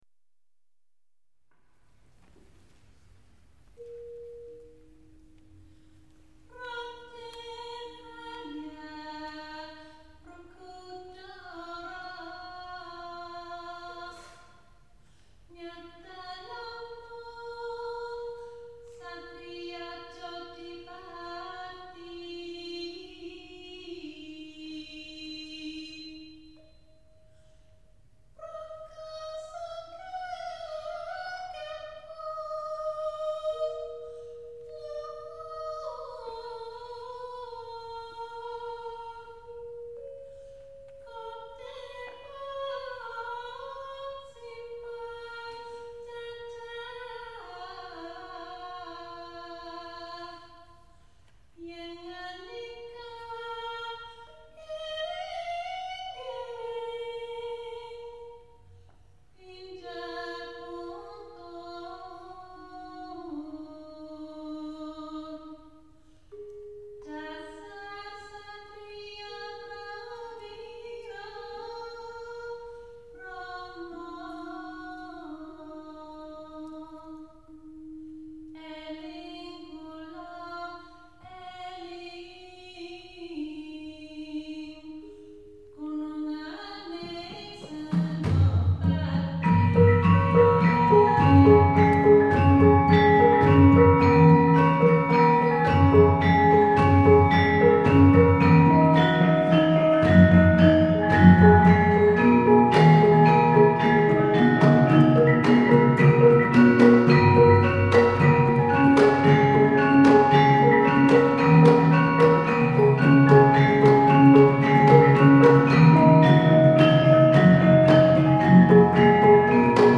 Javanese gamelan music clips.
Played by Kyai Telågå Rukmi , the University of Wisconsin-Madison Javanese gamelan ensemble, on 21 April 2001.